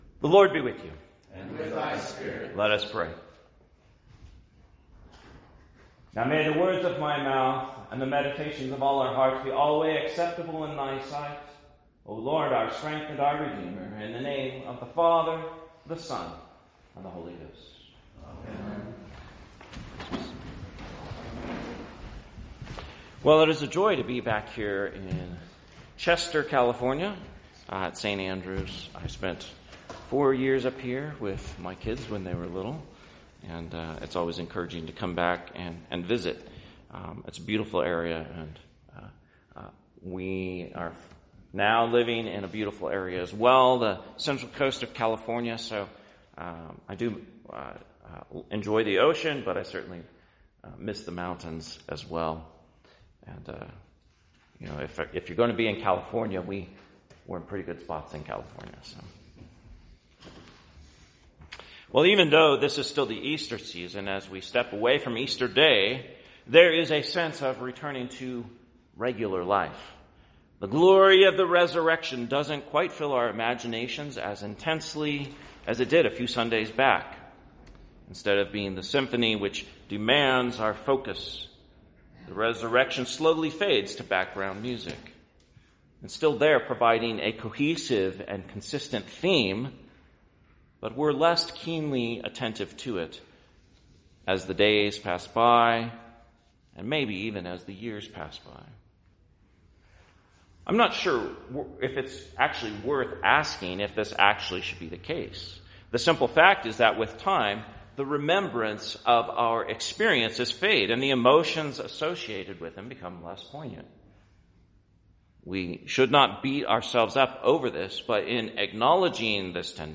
Sermon, 3rd Sunday after Easter, 2025